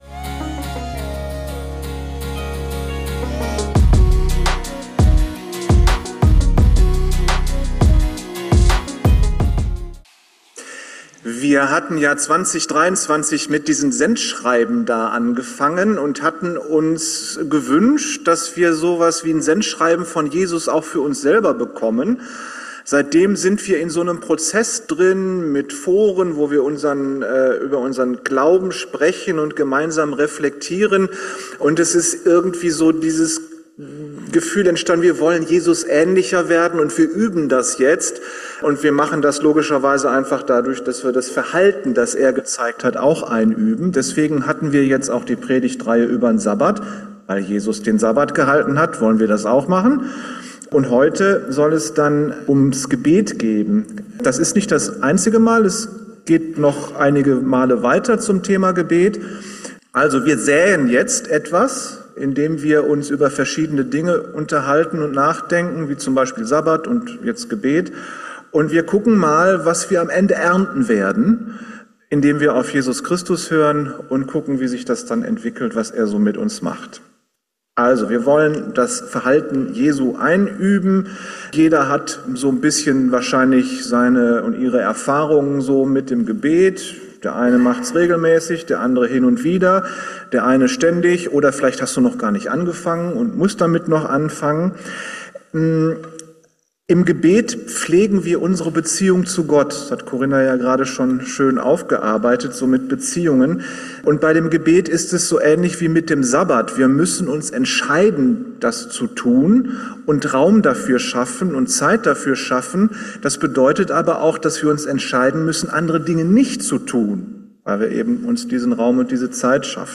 Beten ist Beziehungspflege mit Gott ~ Geistliche Inputs, Andachten, Predigten Podcast